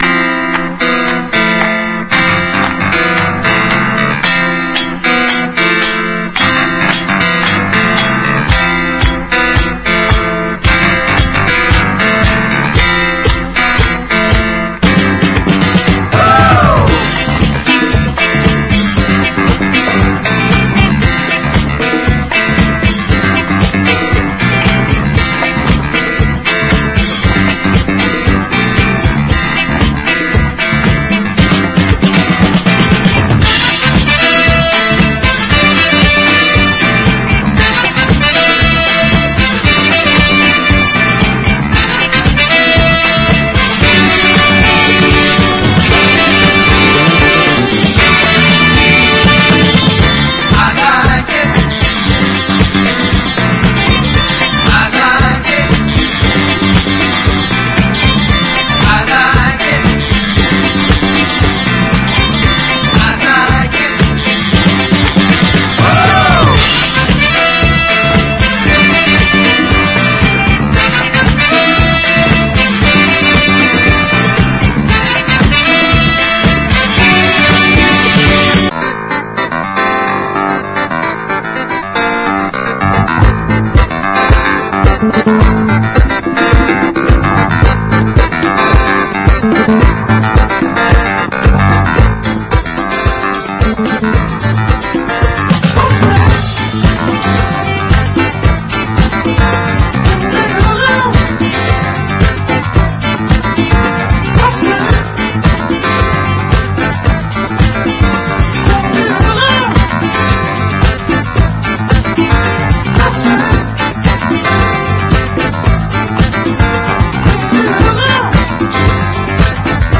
EASY LISTENING / EASY LISTENING / ACCORDION / 口笛
口笛入りのアコーディオン・ラウンジ！ノスタルジックな巴里の香り！
ジャズ寄りの洗練とは異なる、まさに街角のパリを思わせる素朴であたたかな音世界。